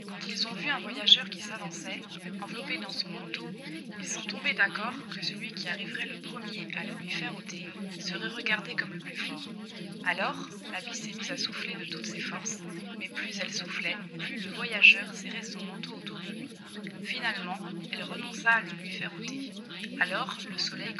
Le signal est ici la voix française de l’ISTS (NFIM french) et le bruit un mélange de 4 ISTS, le tout de -12dB à +12dB de RSB + silence.